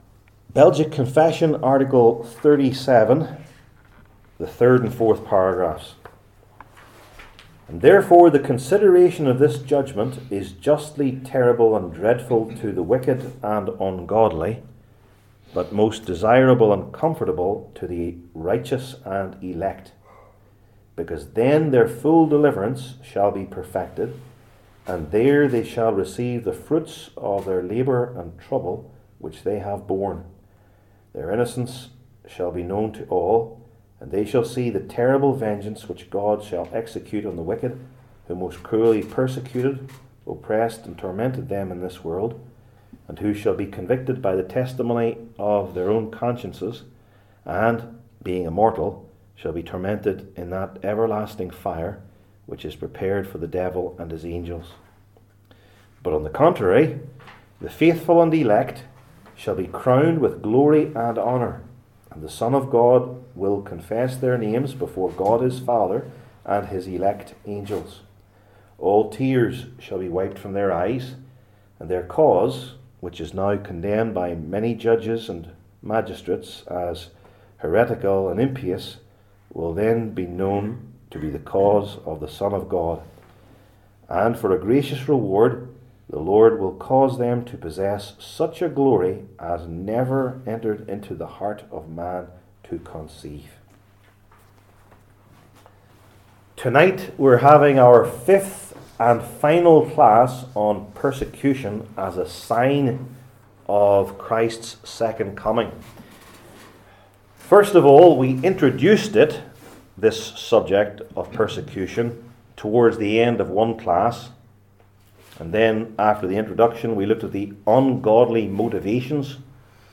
Matthew 24:1-14 Service Type: Belgic Confession Classes THE LAST JUDGMENT …